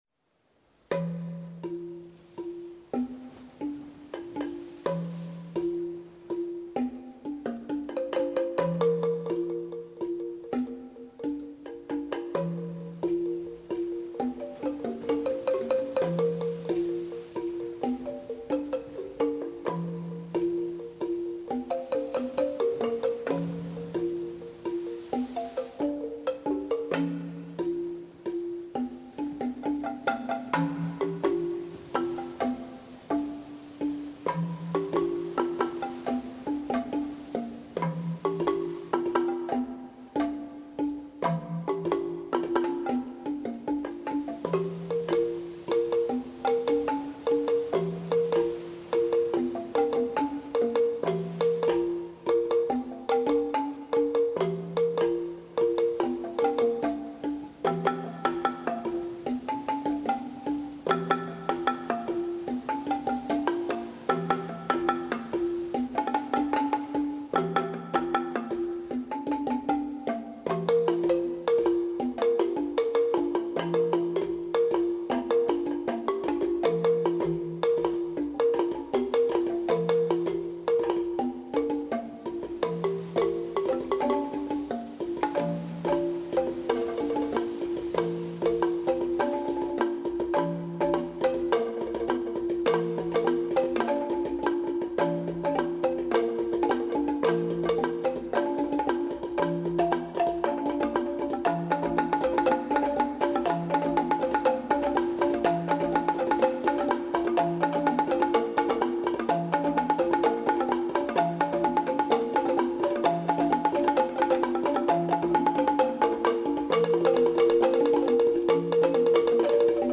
soprano and tenor saxophones
bass, synth programs, percussion
guitar, voices